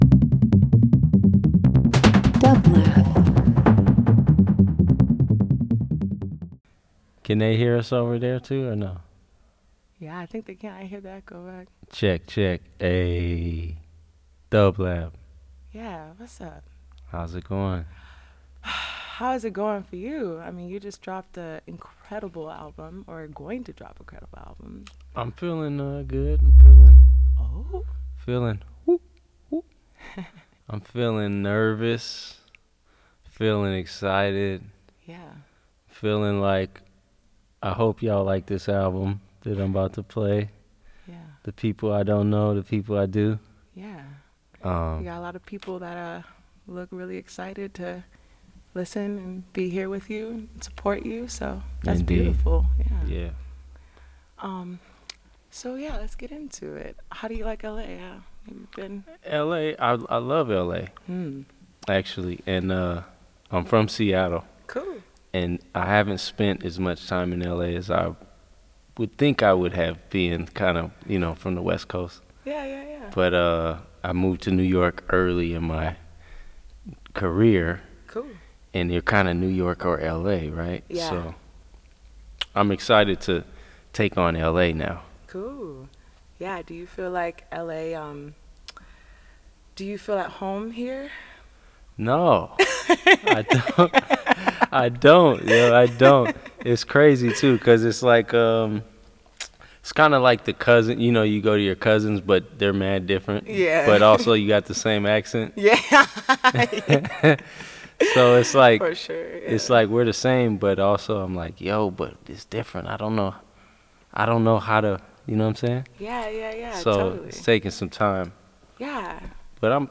In Conversation: ANIMALS album release